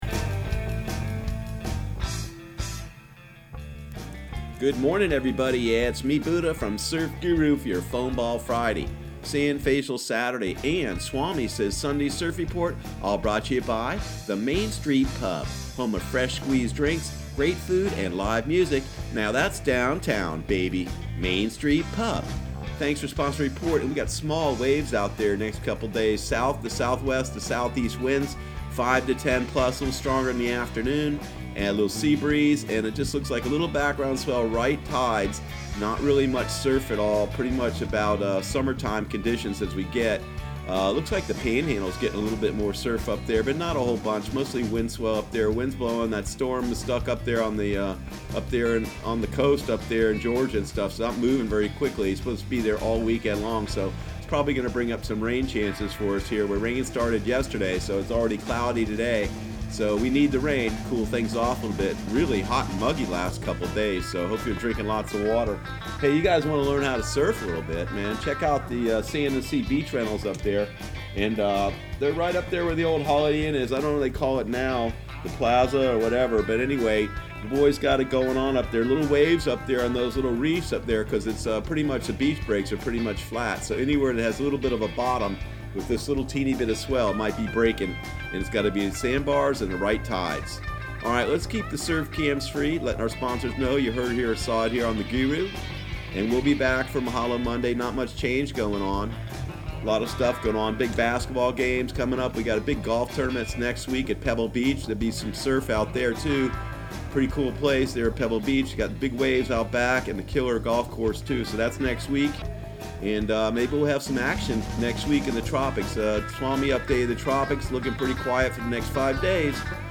Surf Guru Surf Report and Forecast 06/07/2019 Audio surf report and surf forecast on June 07 for Central Florida and the Southeast.